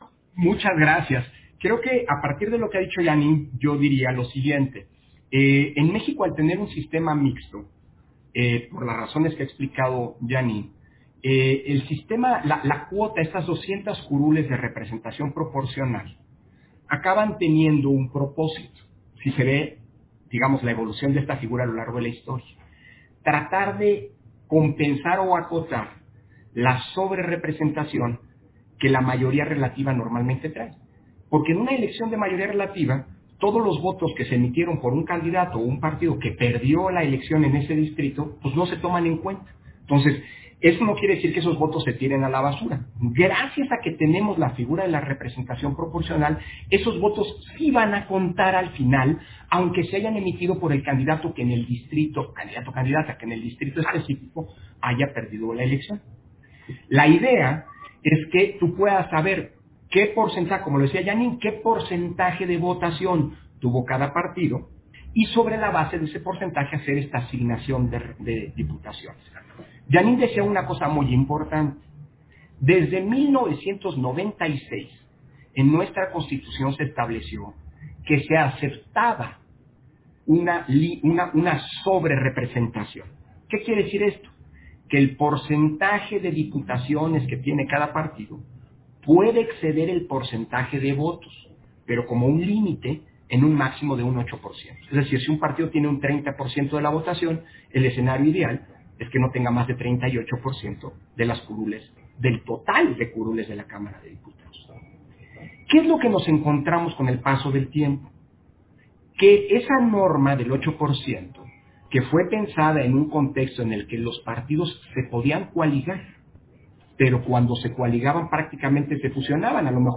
Intervenciones de Lorenzo Córdova, en la Mesa Redonda: Organización y Retos de la Elección